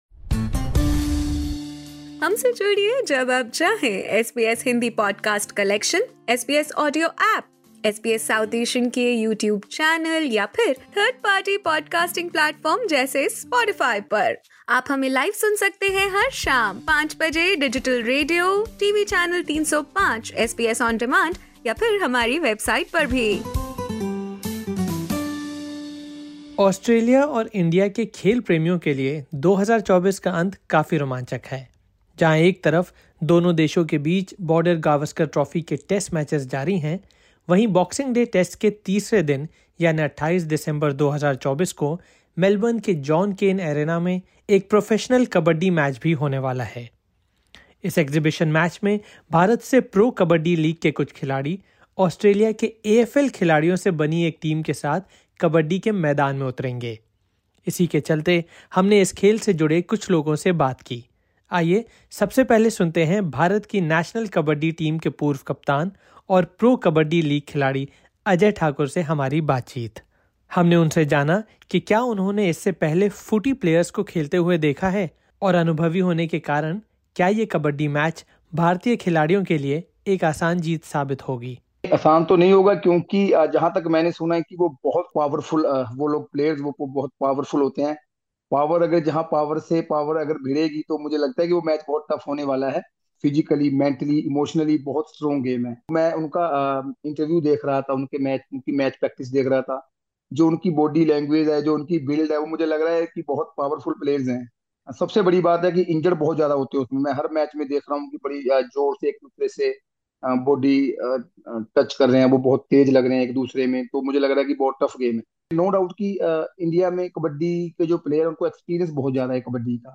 We spoke with Josh Kennedy during a recent practice session for the upcoming Pro Kabaddi League exhibition match.